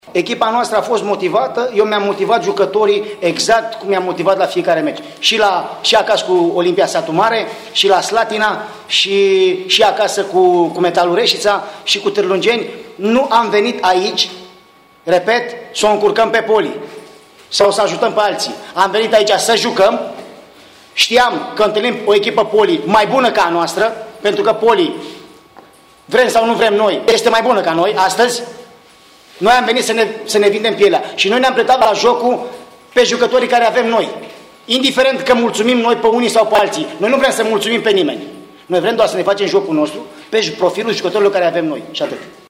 Antrenorii celor două echipe au vorbit după meci